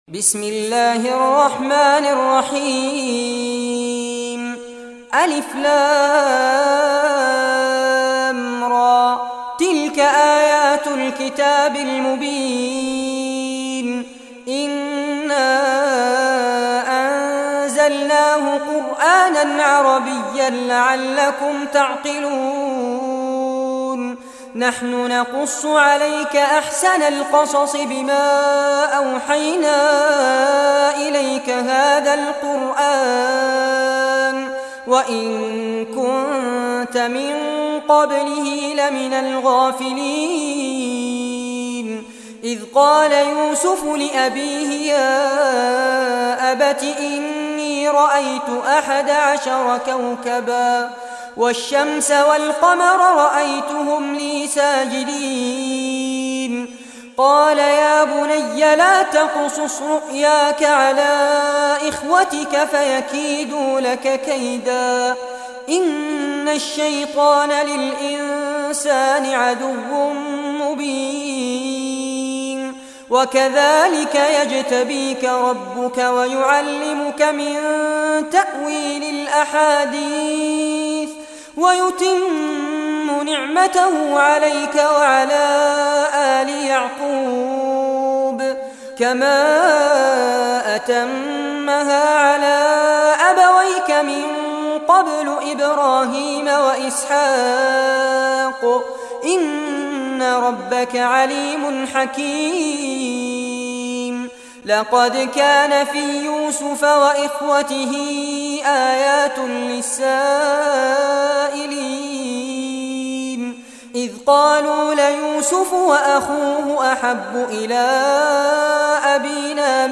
Surah Yusuf Recitation by Sheikh Fares Abbad
Surah Yusuf, listen or play online mp3 tilawat / recitation in Arabic in the beautiful voice of Sheikh Fares Abbad.